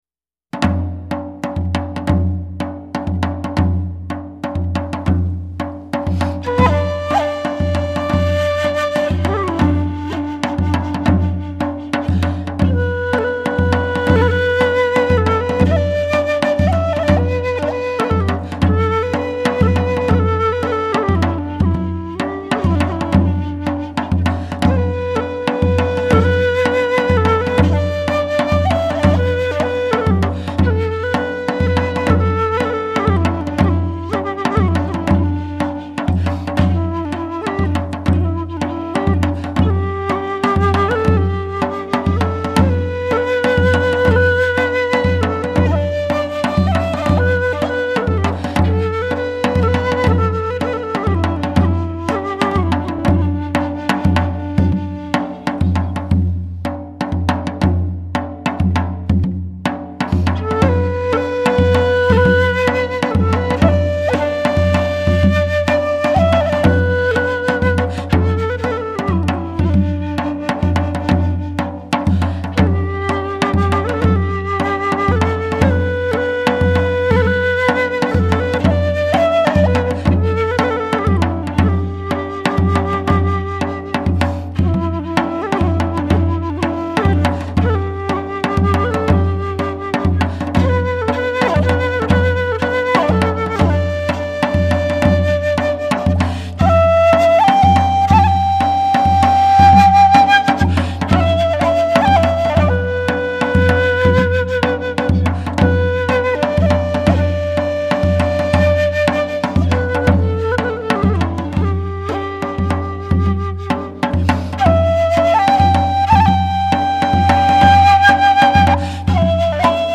它頻響寬闊，尤其是那火爆的動感與深不見底的低頻，更是檢測器材之參考利器。
動中有靜，剛中帶柔，正是本專輯之引人之處。
朝鮮族長鼓以柔韌曼妙的音色，表現輕鬆歡樂、載歌載舞的氣氛